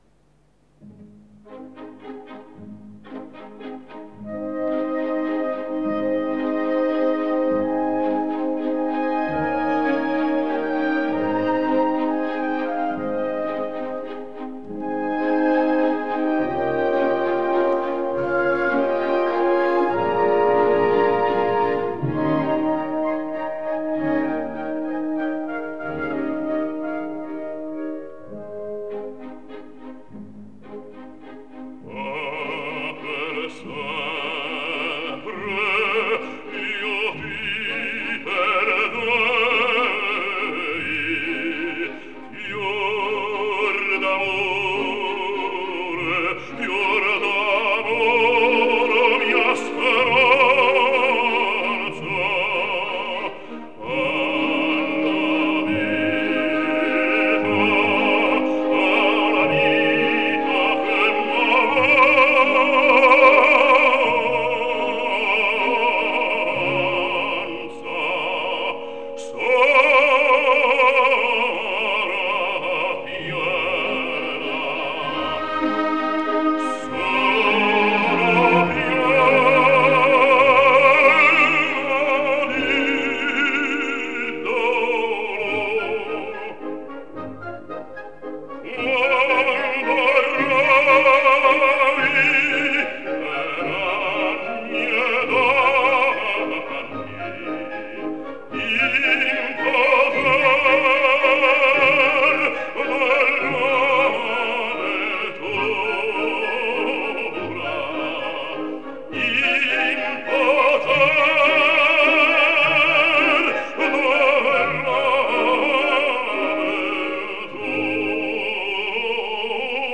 Из пропущенных дат: 10 декабря был день рождения известного итальянского баритона Сесто Брускантини (1919-2003гг)